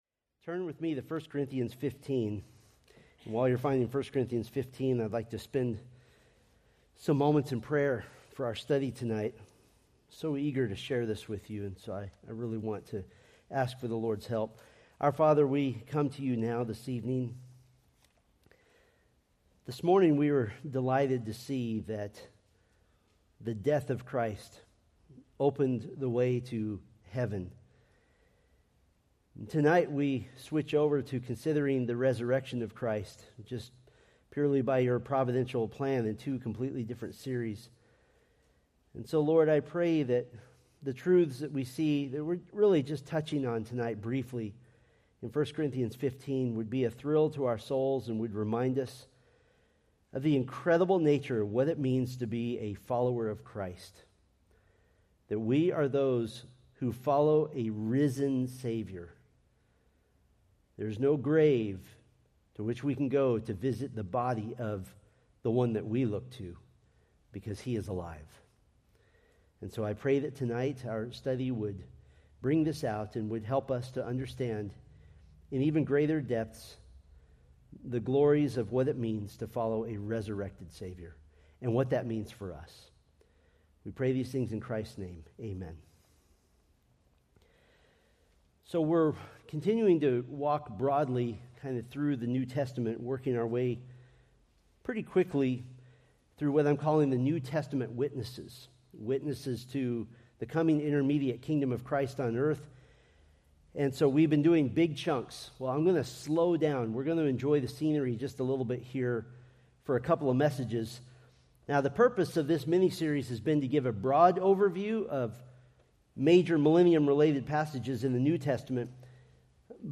Preached December 8, 2024 from 1 Corinthians 15